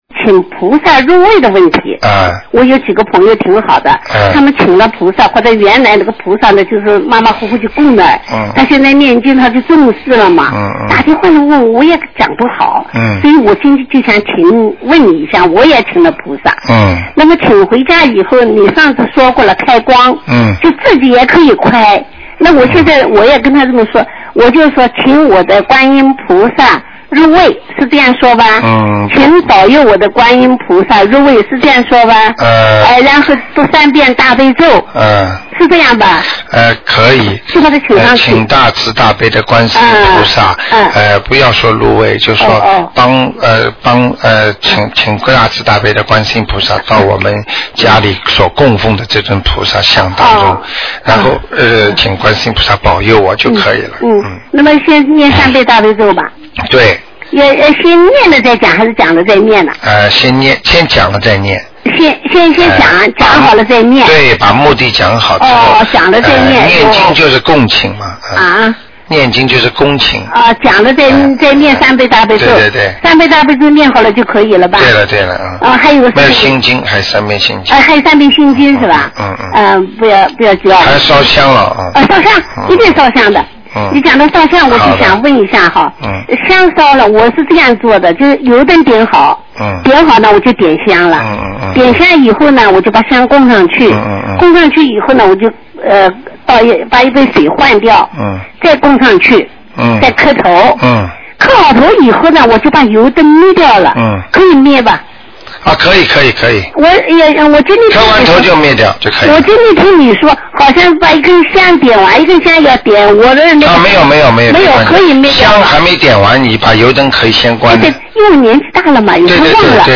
但因对话交流带有语气，文字整理不可能完全还原情境。